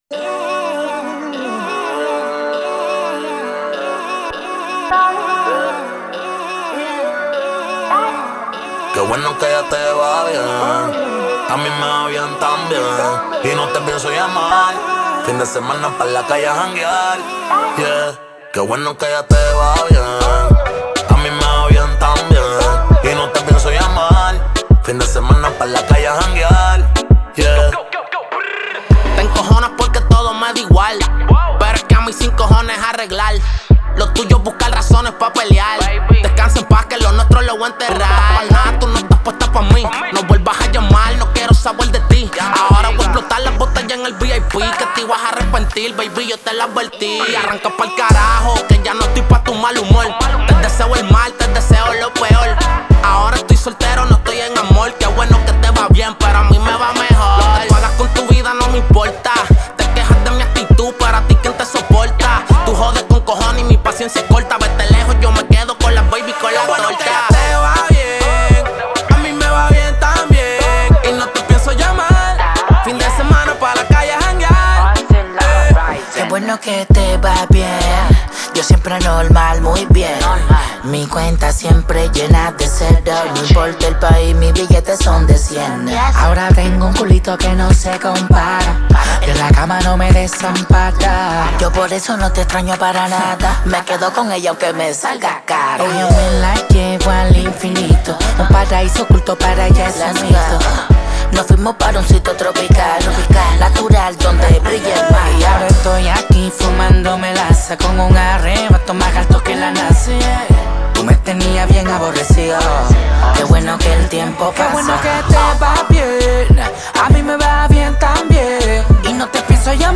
это зажигательный трек в жанре реггетон